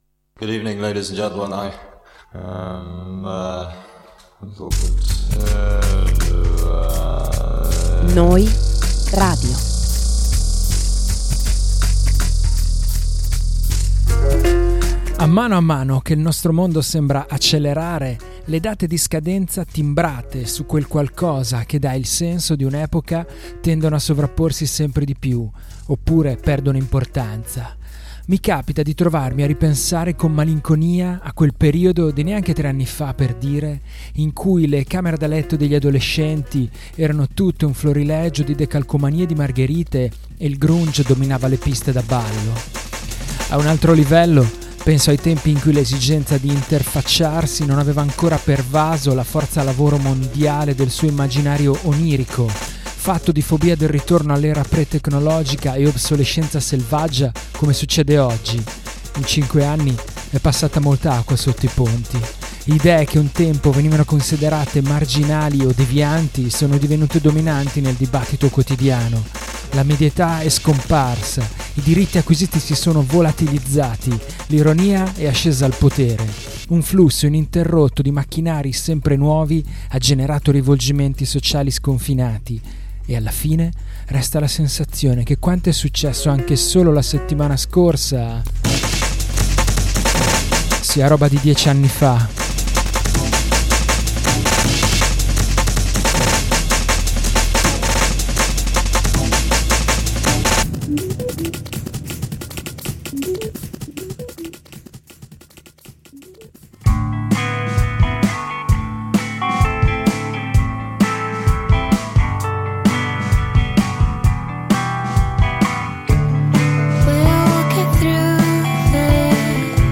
Podcast di novità indiepop, indie rock, shoegaze, post-punk, lo-fi e twee!